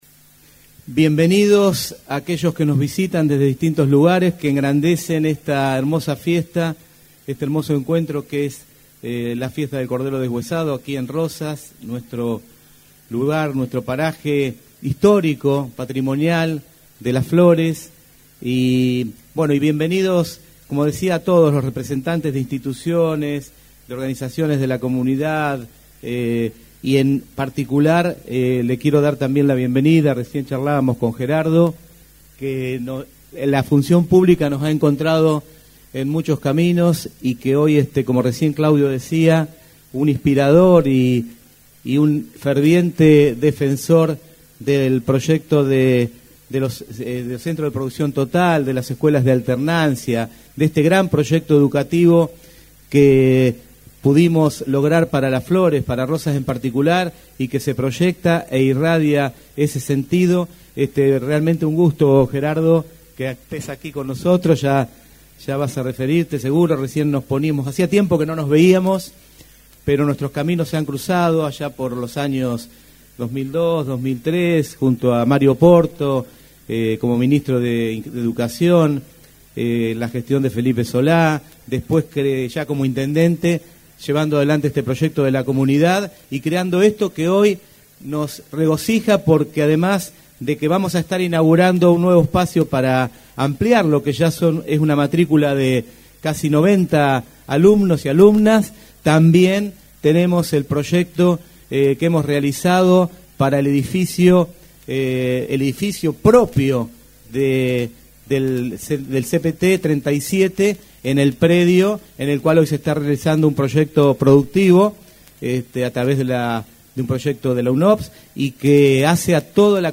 En lo protocolar, se escuchó la reflexión de:
Alberto Gelené Intendente: